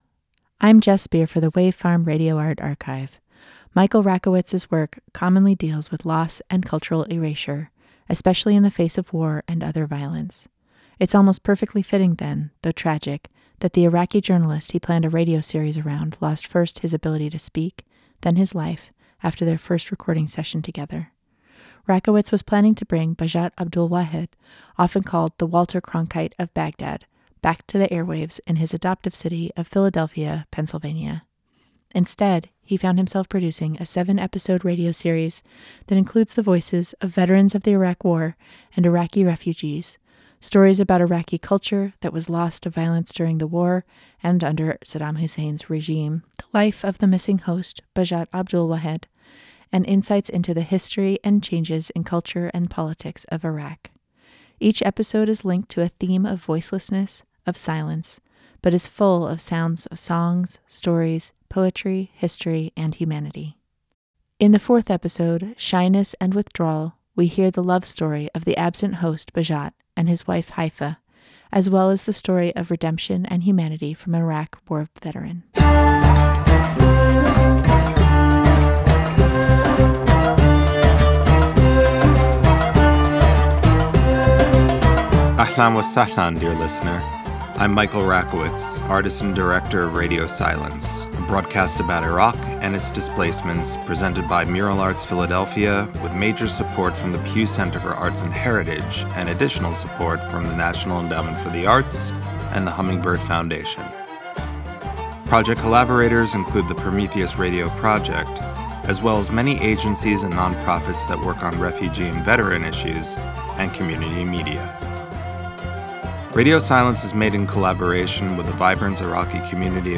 Each episode is linked to a theme of voicelessness, of silence, but is full of sounds of songs, stories, poetry, history, and humanity. As with his other artworks, Rakowitz recruits participants to engage in the art, featuring writing by Iraq war veterans, a radio play by an Iraqi artist, Iraqi music, and interviews with historians, journalists, and everyday people. These sounds weave together different elements of the story of Iraq in the 20th century, sketching out a picture of what was lost, and what is being created both in Iraq and in the diaspora created by decades of colonialism and conflict in Iraq. The series was produced in collaboration with Mural Arts Philadelphia and originally broadcast on WPPM PhillyCAM Radio 106.5 FM in 2015.